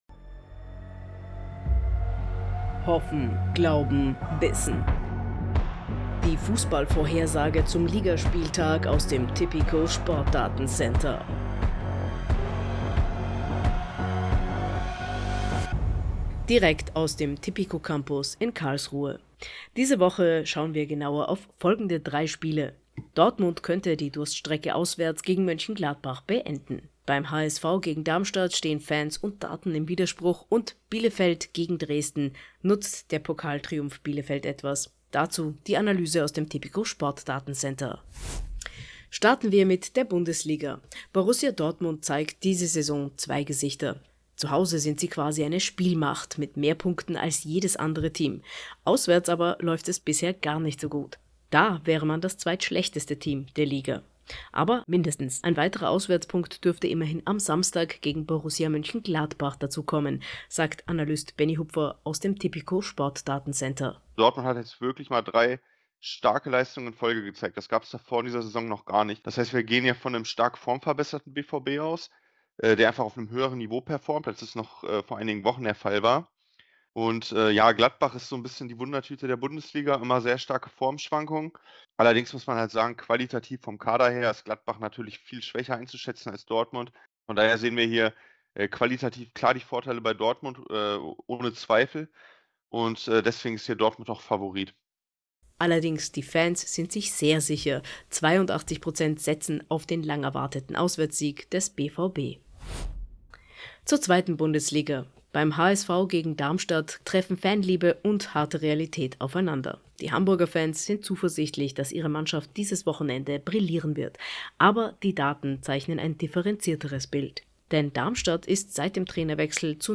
Die Pressemeldung im Audio-Newscast: